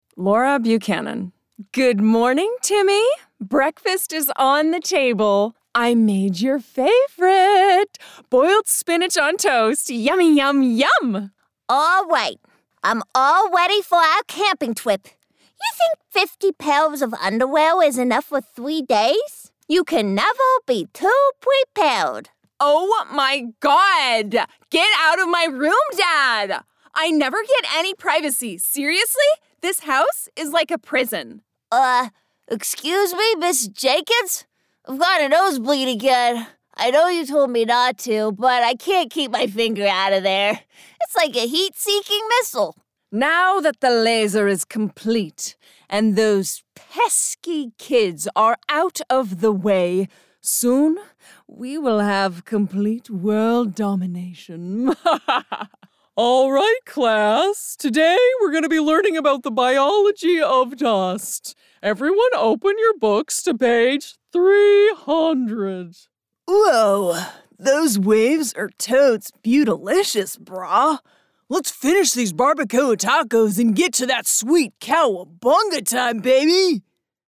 Animation - EN